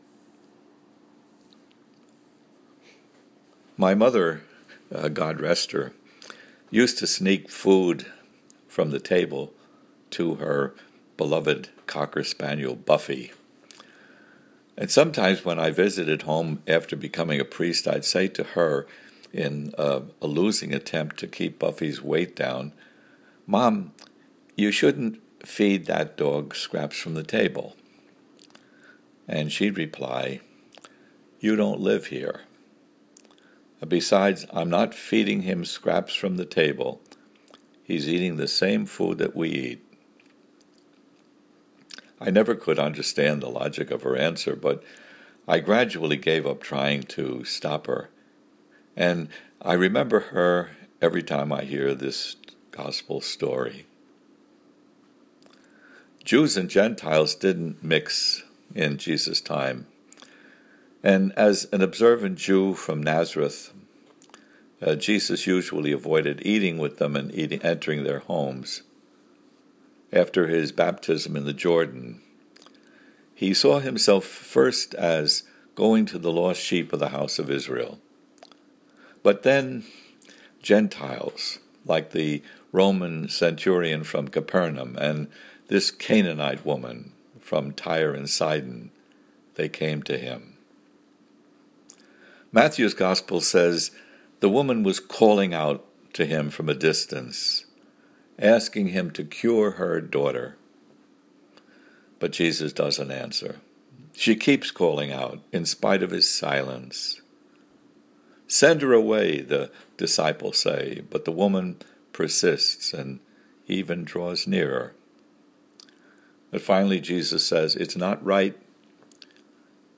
For an audio of the homily: